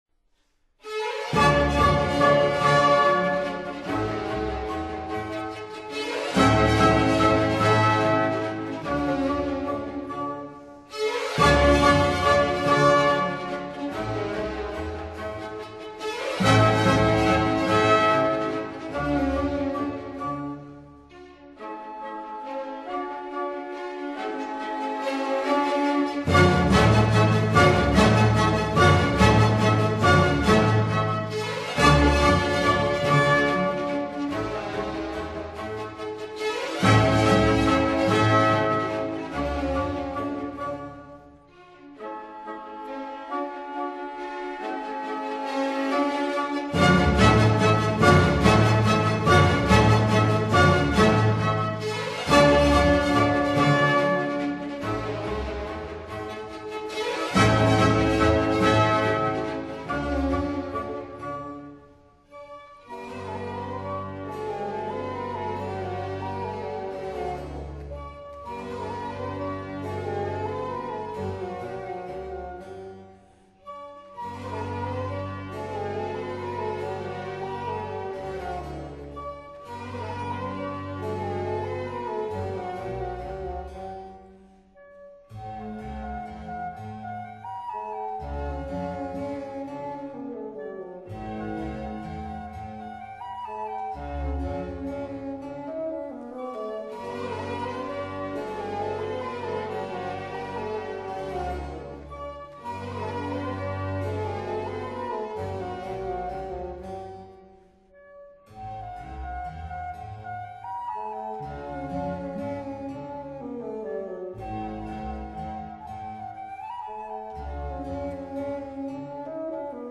Sinfonia
ré majeur
Menuetto majestoso    [0:02:47.70]